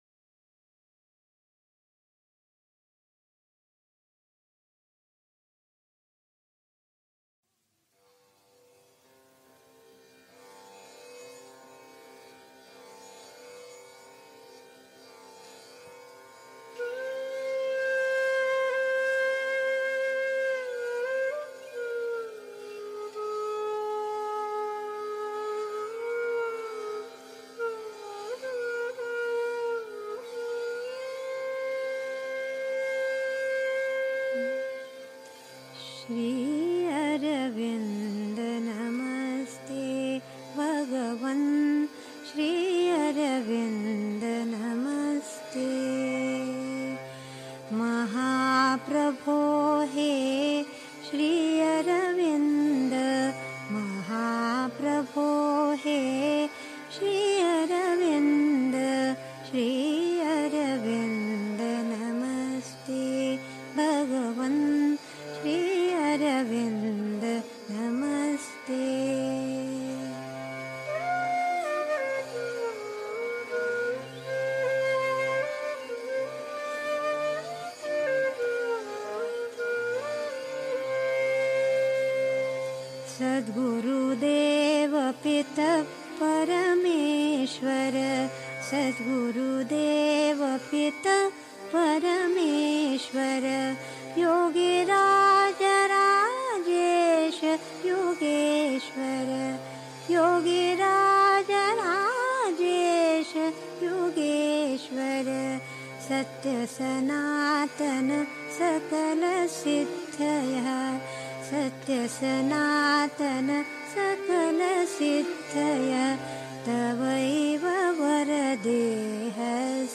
1. Einstimmung mit Musik. 2. Unser Ziel (Sri Aurobindo, CWSA Vol. 12, p. 98) 3. Zwölf Minuten Stille.